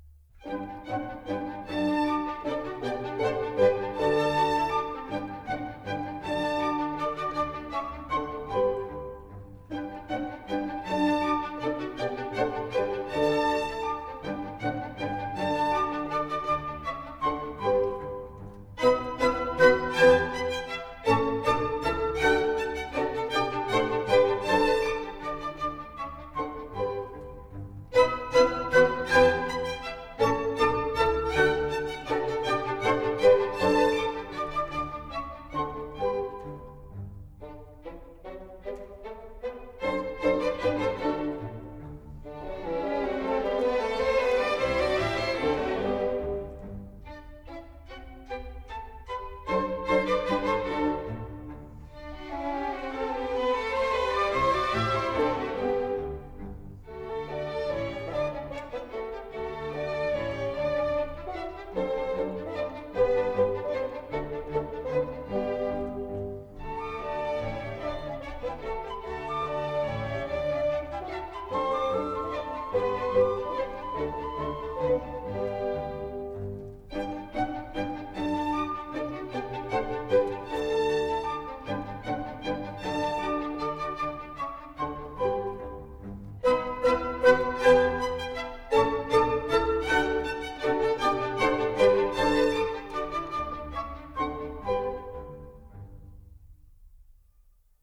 » nhac-khong-loi
D